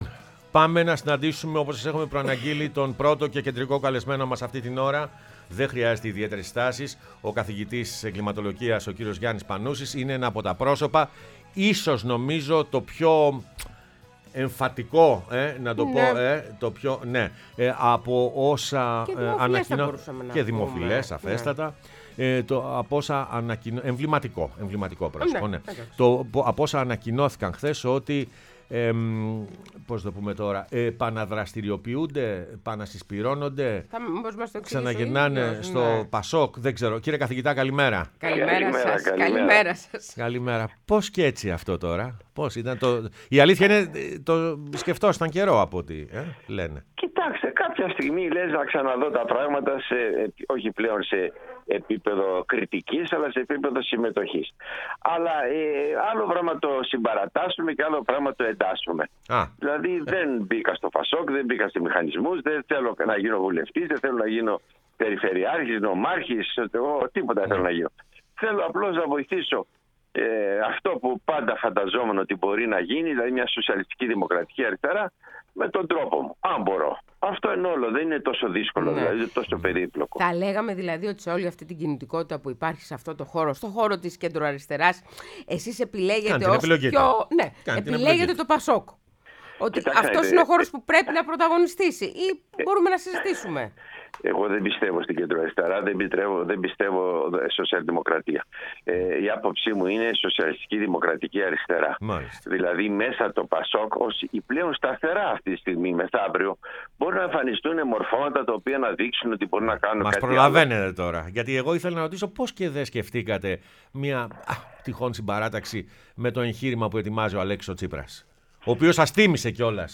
O Γιάννης Πανούσης, Καθηγητής Εγκληματολογίας, μίλησε στην εκπομπή «Πρωινές Διαδρομές»